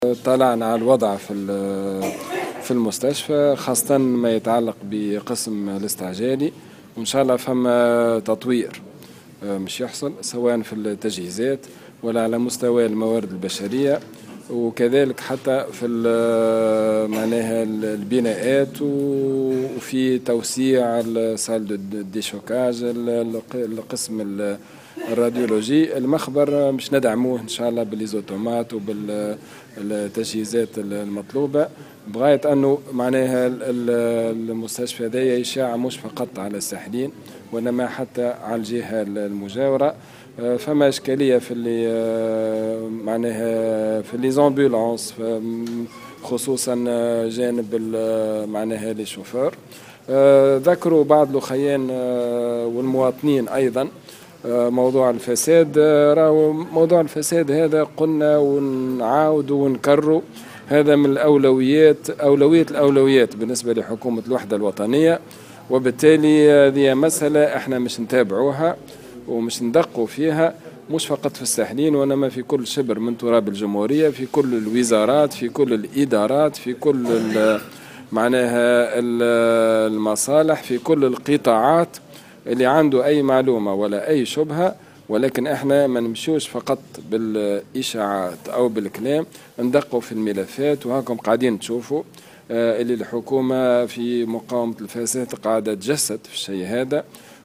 Les mesures concernent le renforcement de l'effectif, l'expansion des services des urgences et de radiologie et le développement du laboratoire qui sera doté de nouveaux appareils, dans la mesure des moyens permis par le budget du ministère, a indiqué le ministre au micro de Jawhara FM.